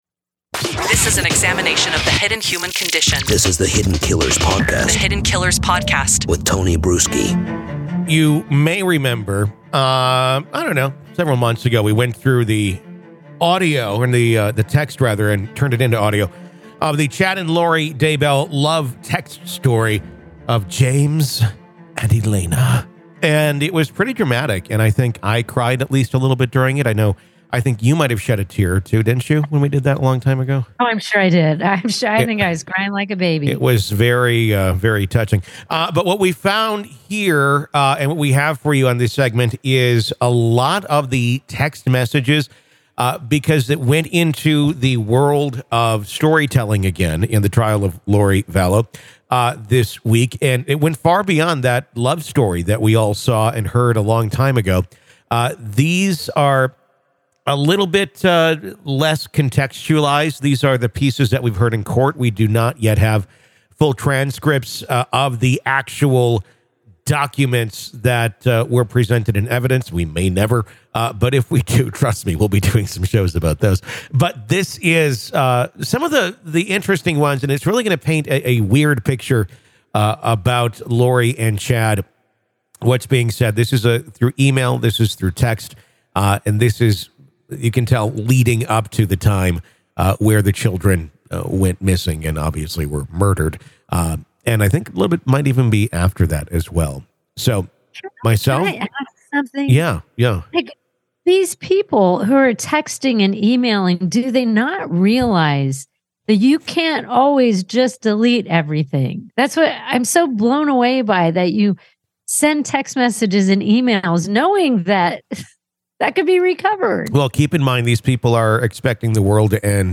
A New Dramatic Reading Of Lori & Chad Dabell's New Text Messages